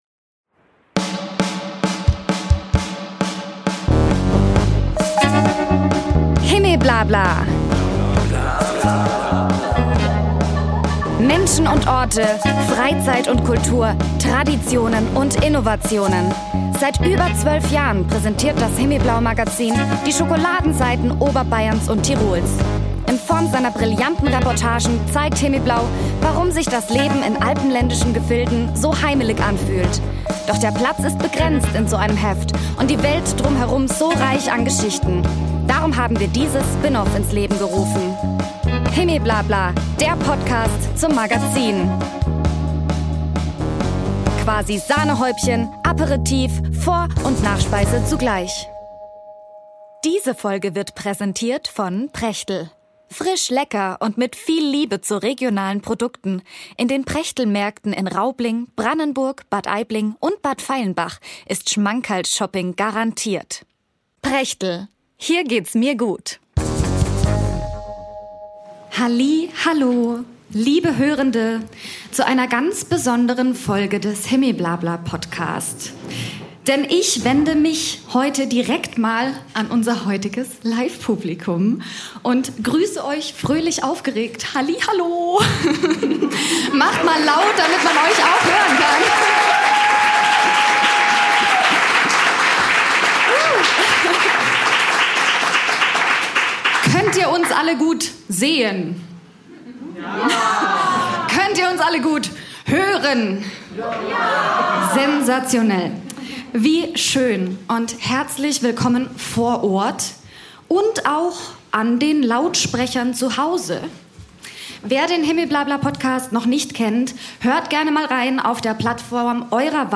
Folge 38 – Diese Folge kommt direkt von der Bühne. Am 7. März 2026 wurde der himmeblabla Podcast live beim Festival mit V in der Alten Druckerei in Rosenheim aufgezeichnet. Fröhliches Publikum und tolle GästInnen vor Ort, Mikrofone an, Lampenfieber auf Anschlag.
Es wird gelacht, diskutiert, geschnipst, gestampft und zwischendurch kurz darüber nachgedacht, warum Männer manchmal im Dunkeln die Straßenseite wechseln sollten.